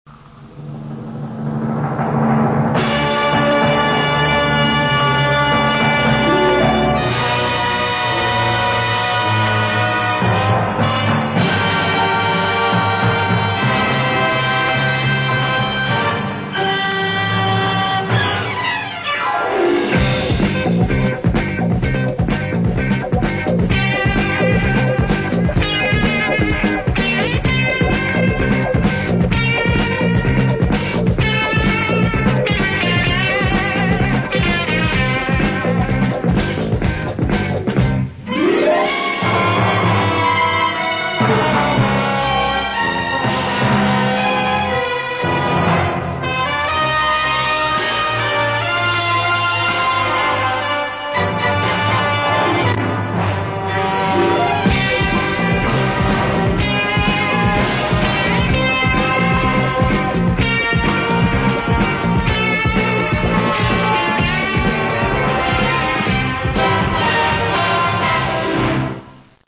Sigla iniziale della prima serie (mp3)